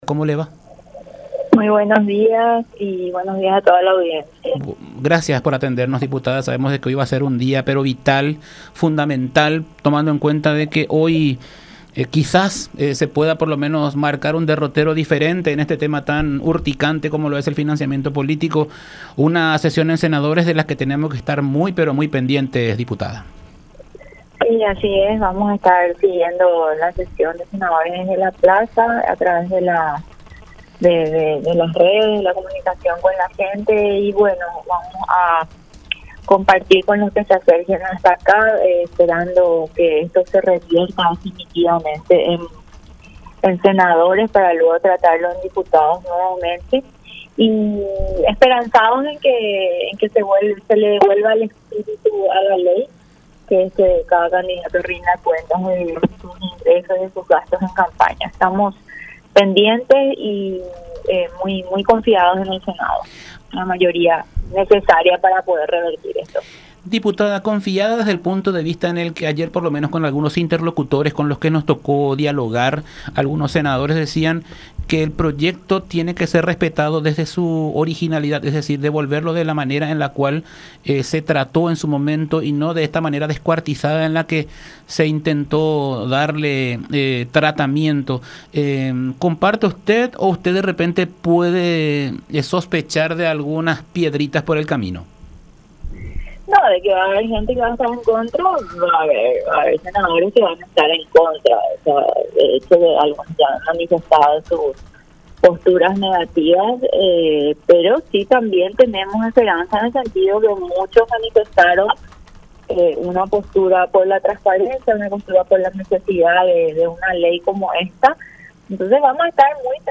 Rocío Vallejo, diputada de Patria Querida.
“Vamos a estar siguiendo la sesión desde la Plaza, compartiendo con la gente que se acerque hasta aquí. Esperanzados en que se vuelva al proyecto original, pero estimo que como es normal, hay senadores que están en contra”, expresó Vallejo en diálogo con La Unión.